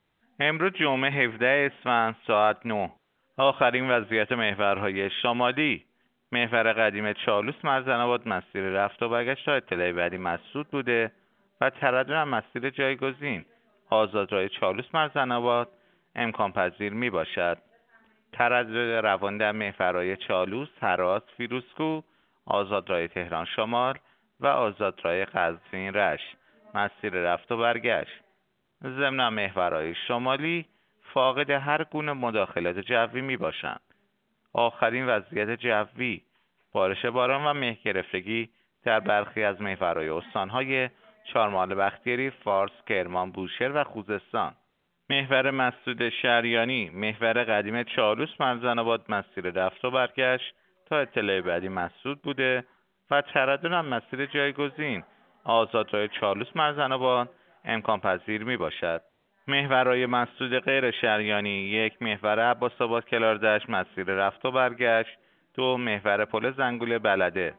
گزارش رادیو اینترنتی از آخرین وضعیت ترافیکی جاده‌ها ساعت ۹ هفدهم اسفند؛